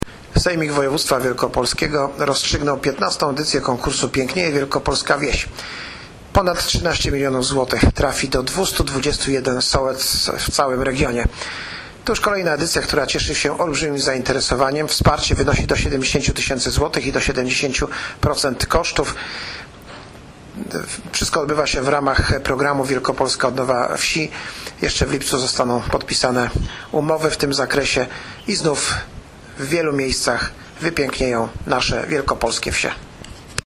Wypowiedź Wicemarszałka K. Grabowskiego dot. wyników XV edycji konkursu Pięknieje Wielkopolska Wieś
Wypowiedz-Wicemarszalka-K.-Grabowskiego-dot.-wynikow-XV-edycji-konkursu-Pieknieje-Wielkopolska-Wies.mp3